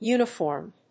発音記号
• / ˈjunʌˌfɔrmd(米国英語)